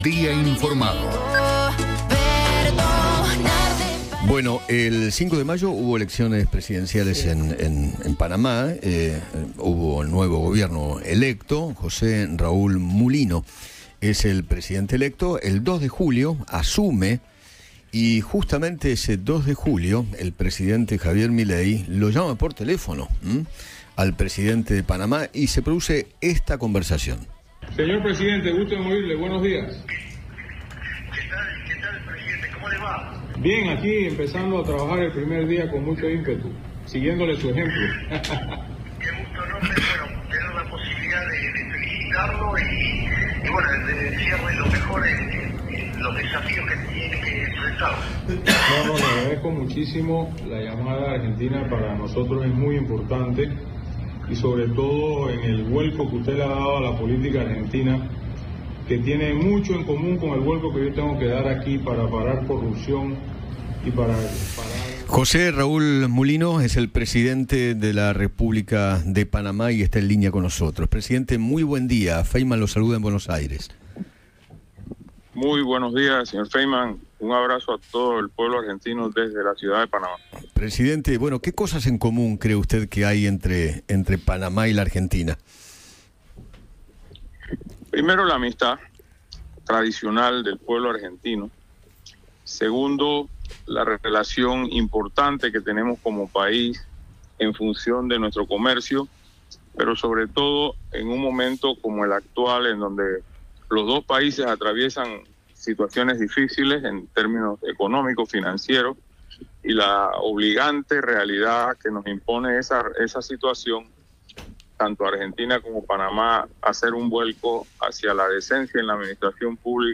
José Raúl Mulino, presidente de Panamá, habló con Eduardo Feinmann sobre su triunfo en las elecciones y comparó la situación que recibe en su país con “la herencia populista” en Argentina.